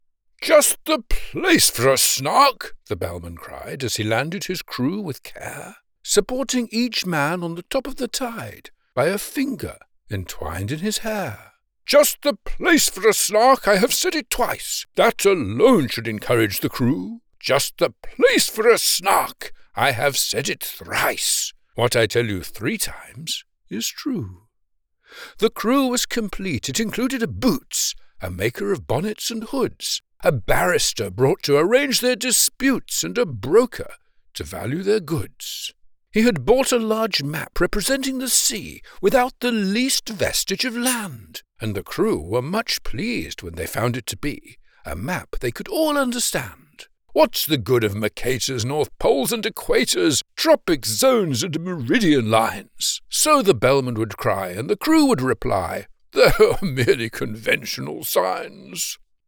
British Children's Book Narrator: